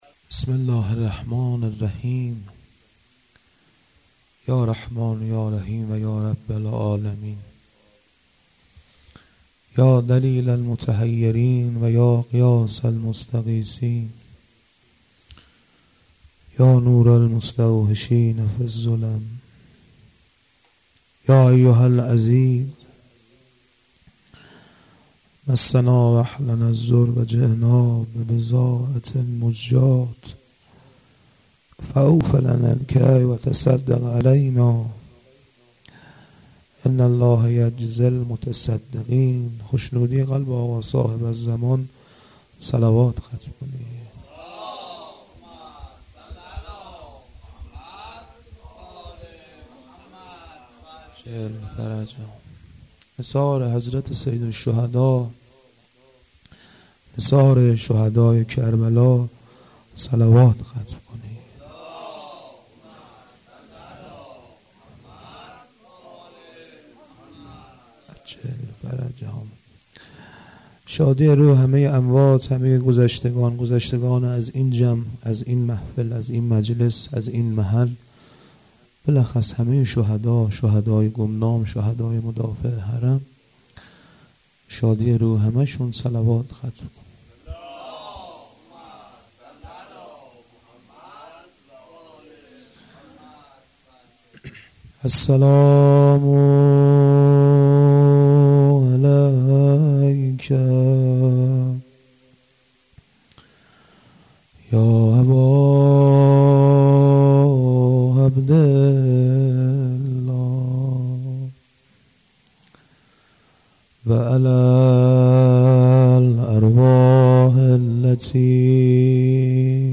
مداحی
هیئت حضرت رقیه س (نازی آباد)